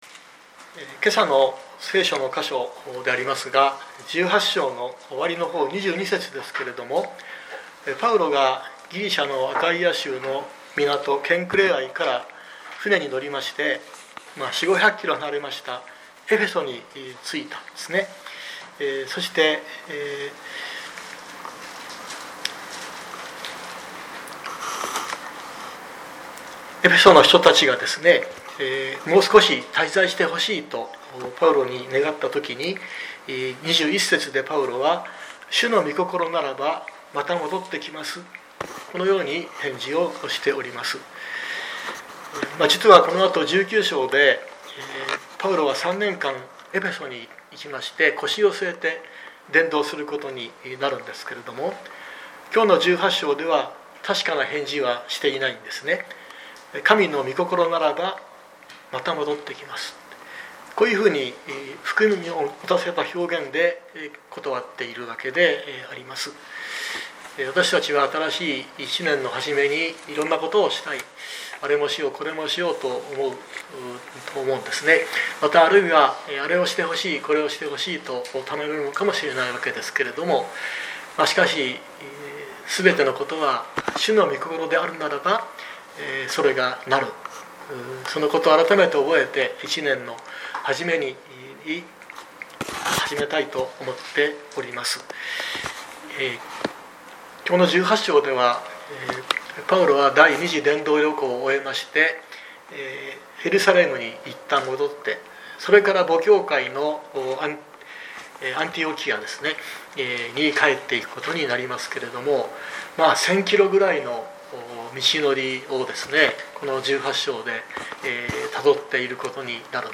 2026年01月04日朝の礼拝「神の御心ならば」熊本教会
説教アーカイブ。